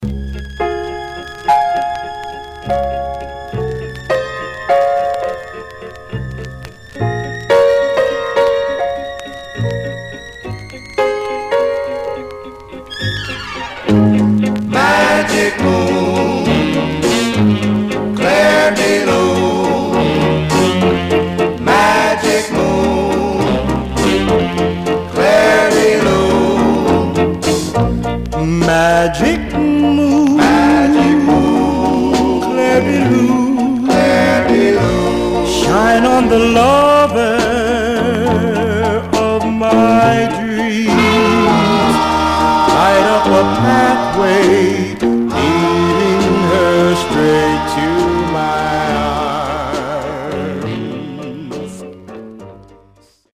Some surface noise/wear Stereo/mono Mono
Male Black Group Condition